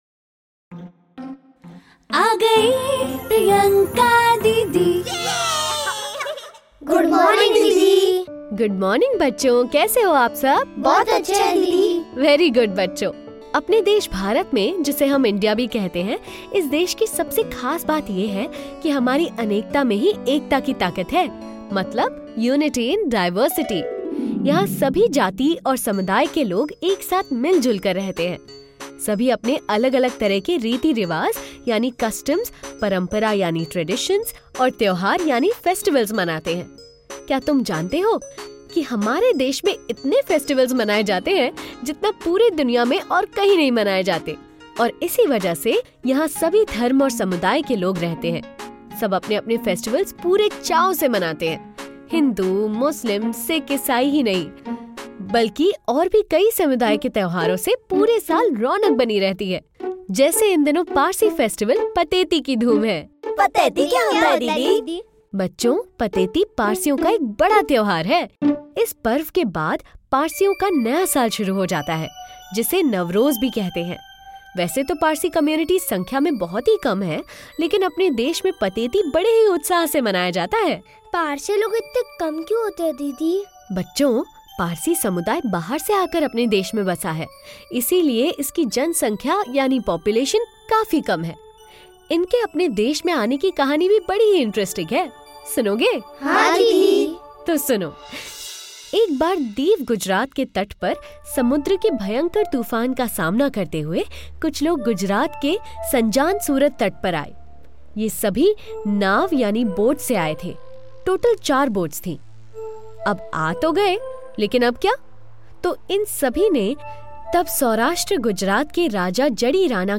Genres: Education for Kids, Kids & Family, Parenting